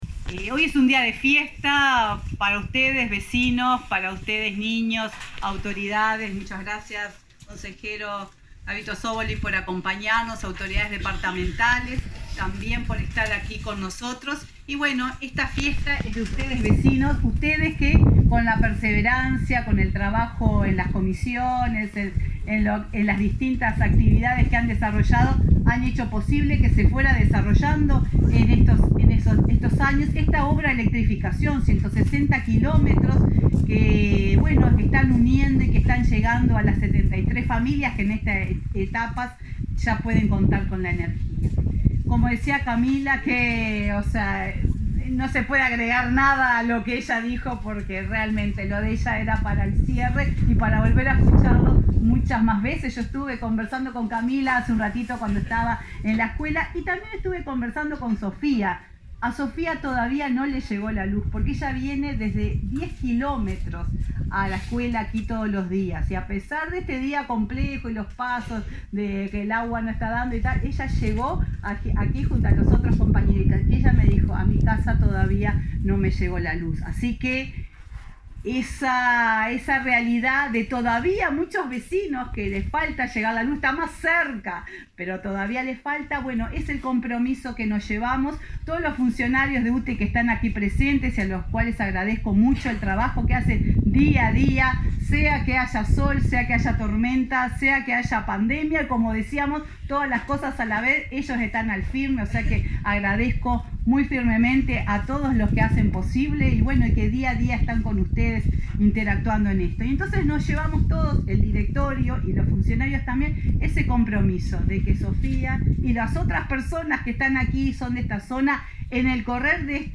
Palabras de la presidenta de UTE, Silvia Emaldi